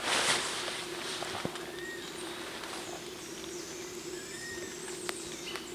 Burlisto Corona Negra (Myiarchus tuberculifer)
Nombre en inglés: Dusky-capped Flycatcher
Fase de la vida: Adulto
Localidad o área protegida: Reserva Natural Privada Ecoportal de Piedra
Condición: Silvestre
Certeza: Vocalización Grabada
burlisto-cabeza-negra.mp3